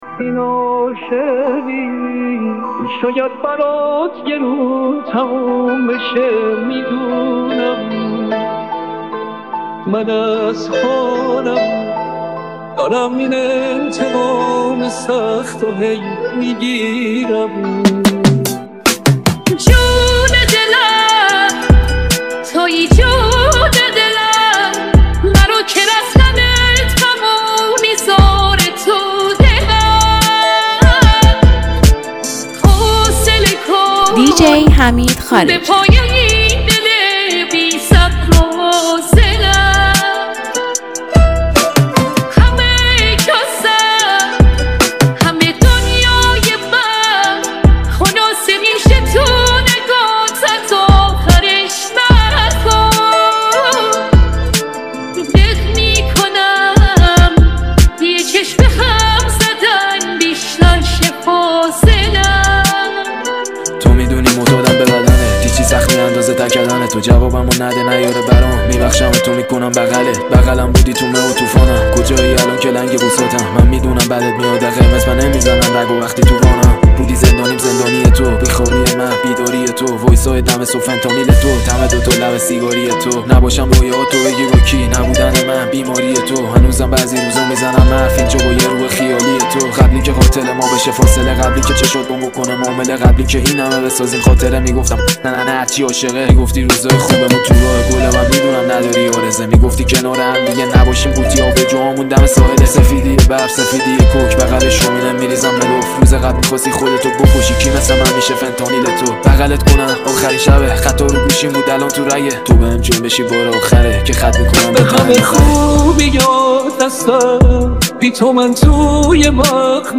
تجربه‌ای شنیدنی و متفاوت از ترکیب سبک‌های مختلف موسیقی است
موزیک ریمیکس